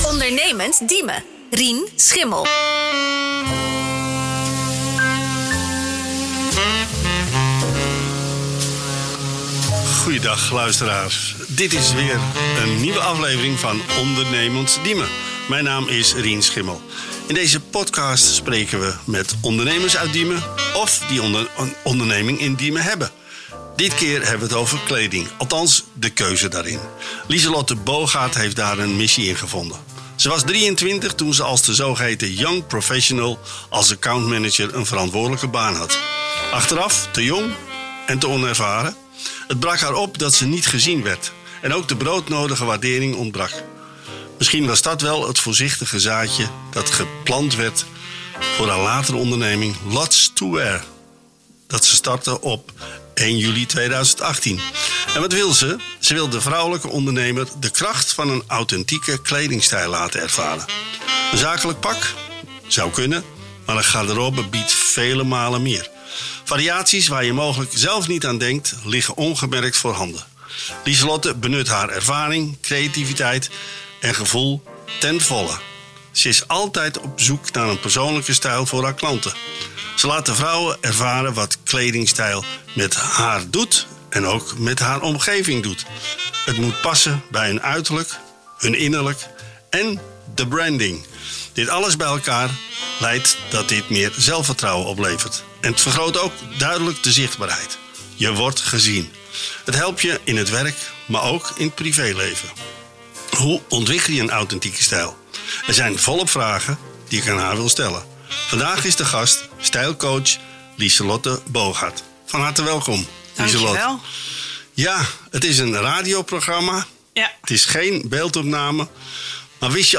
Hierin worden Diemense ondernemers geïnterviewd over hun bedrijf en leer je de ondernemer achter het bedrijf beter kennen.